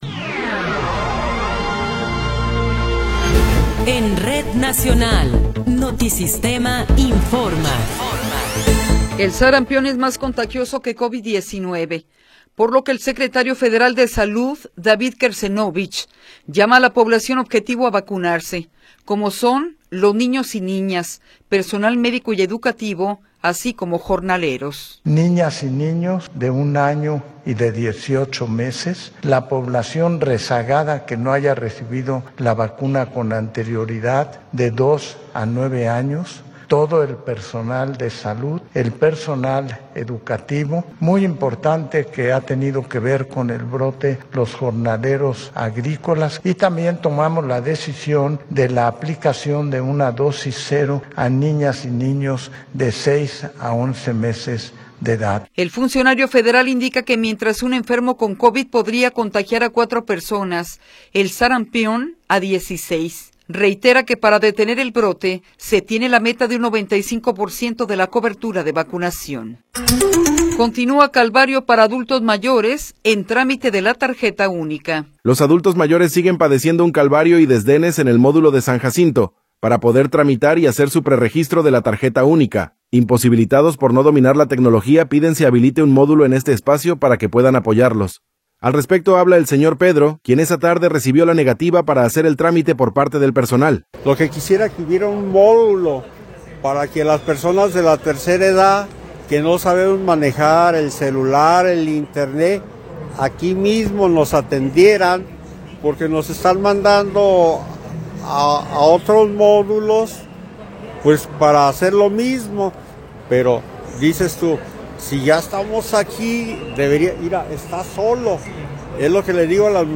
Noticiero 16 hrs. – 21 de Enero de 2026
Resumen informativo Notisistema, la mejor y más completa información cada hora en la hora.